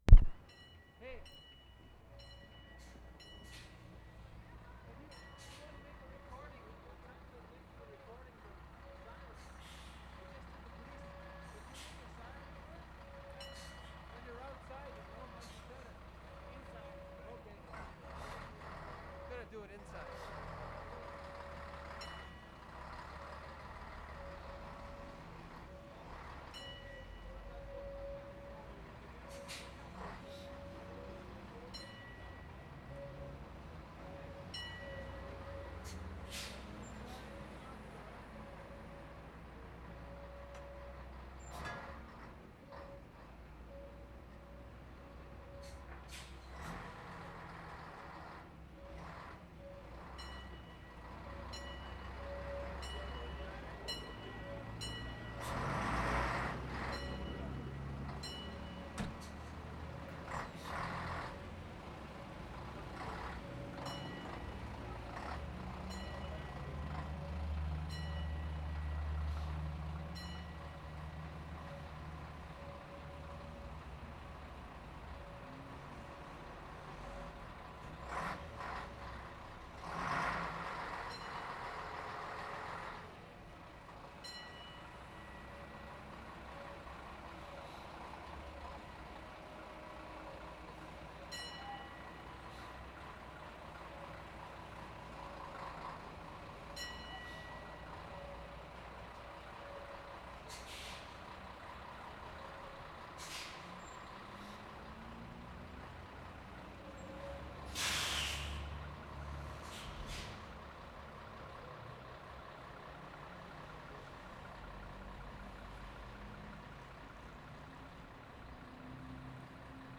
Stockholm, Sweden Feb. 9/75
STOCKHOLM FIRE HORN AND BELLS
6-10. Note aspiration effect of the two note motif: one seems to breathe in, the other out.